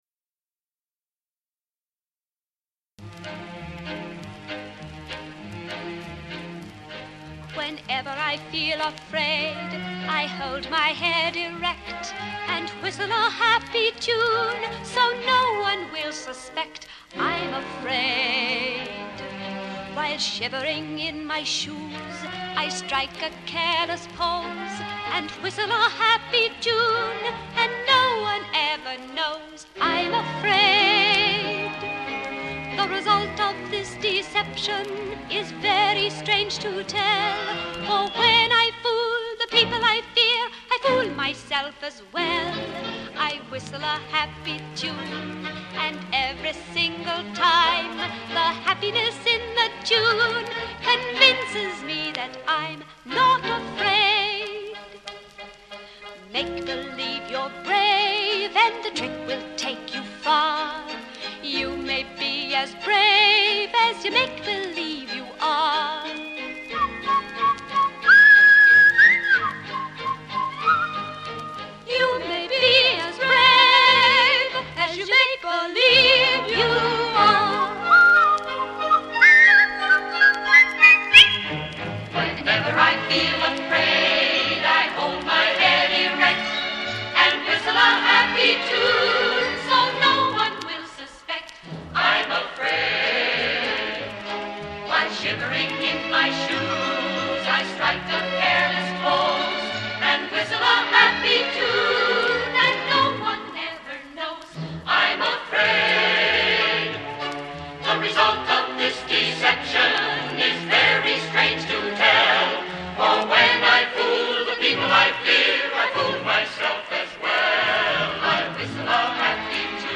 HIGH FIDELITY STEREOPHONIC RECORDING